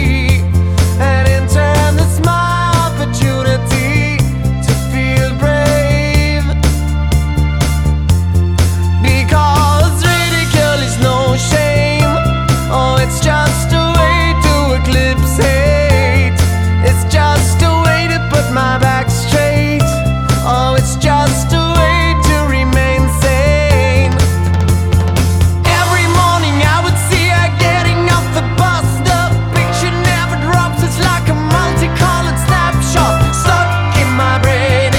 Pop Rock
Glam Rock
Жанр: Поп музыка / Рок / Альтернатива